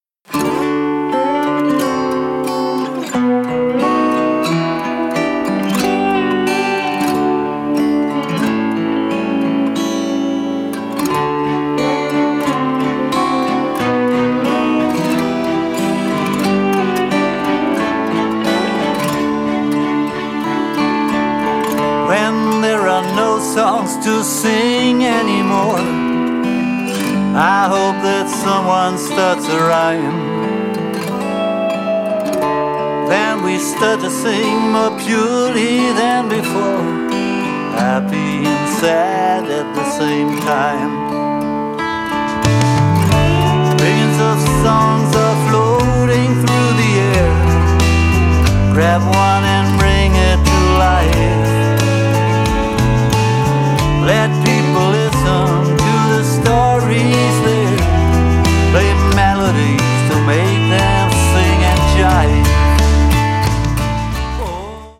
My journey towards Americana continues…
Vocals and harp
Drums, keyboards, string arrangement
Background vocals on 3, 8